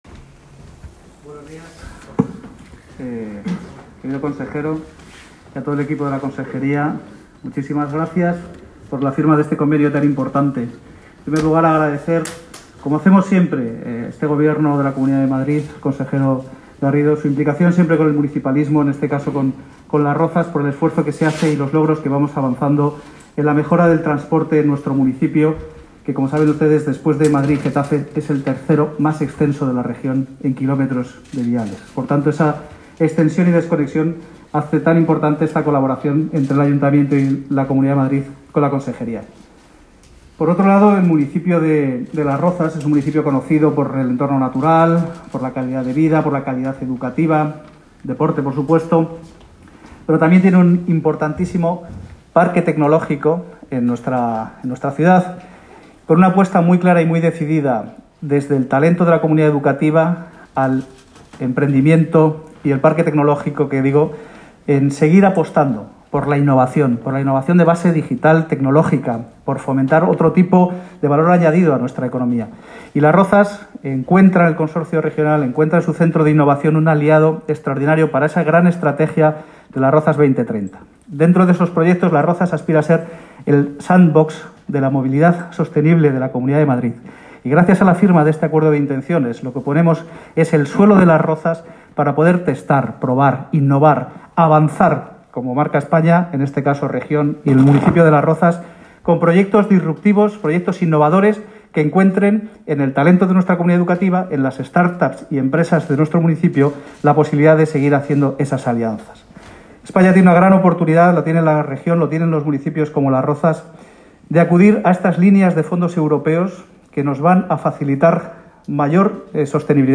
Declaraciones José de la Uz252.m4a